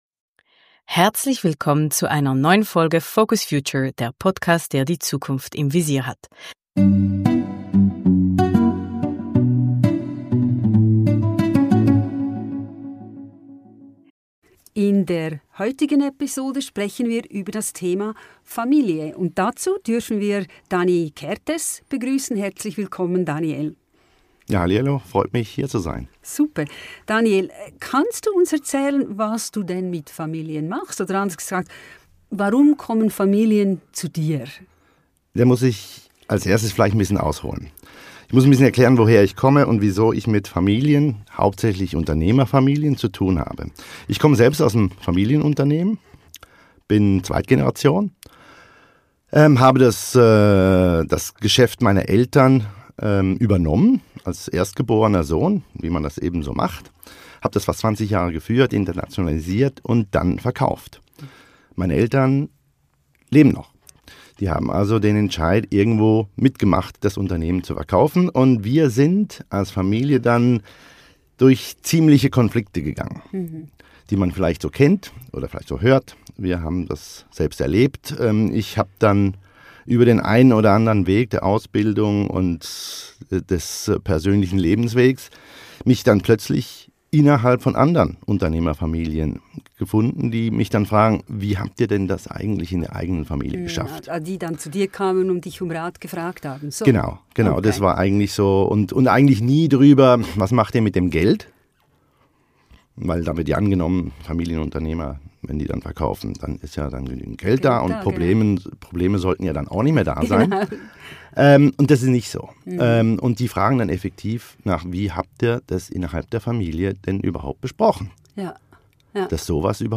Ein interessantes Gespräch über Kommunikation und Entscheidungsfindung in einer Familie, und was es braucht, damit eine Familie glücklich und erfolgreich ist.